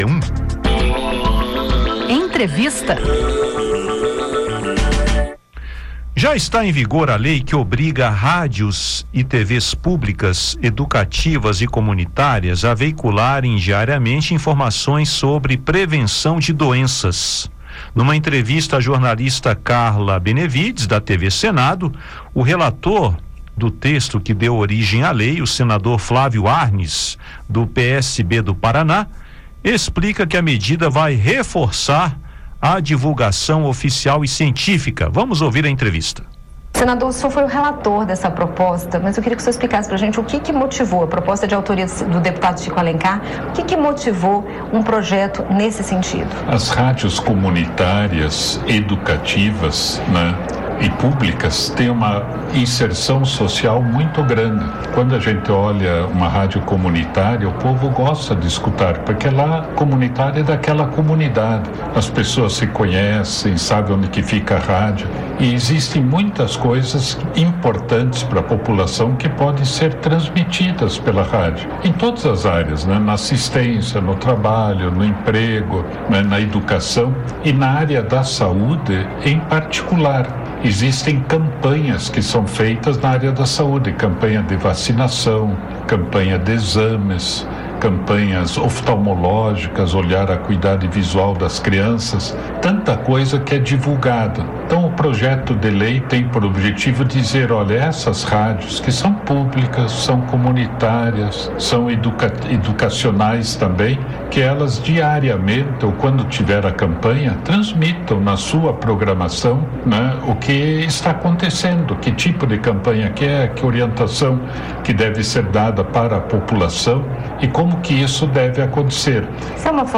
A medida pretende combater a desinformação e reforçar campanhas de saúde pública, como vacinação e prevenção de doenças graves. Ouça a entrevista e entenda como a lei busca proteger a saúde da população.